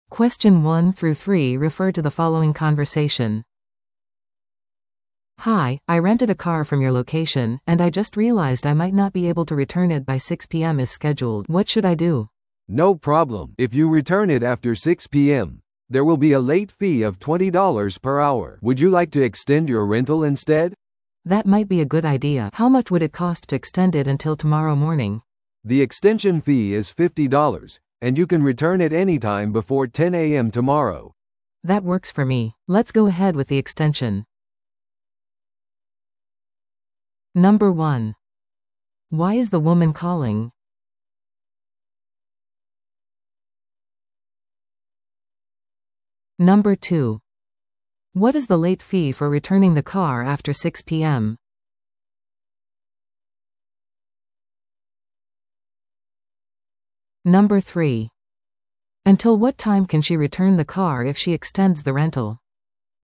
No.1. Why is the woman calling?